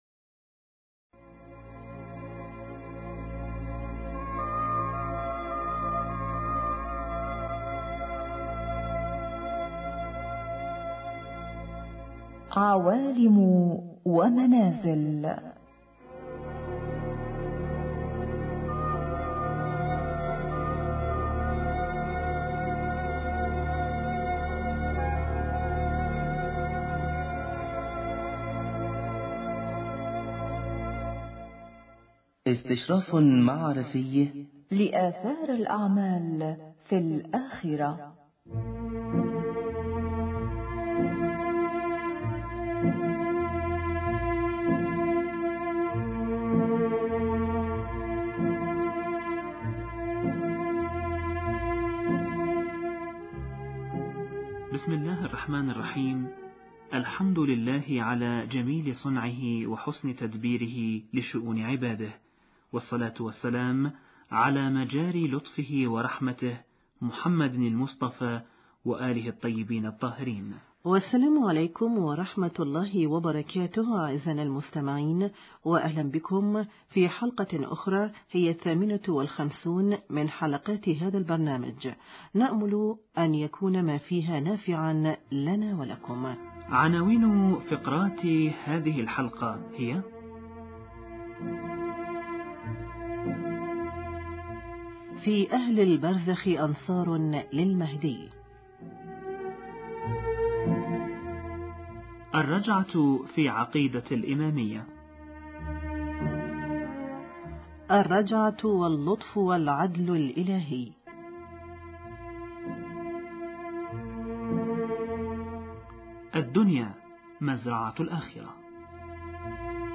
حوار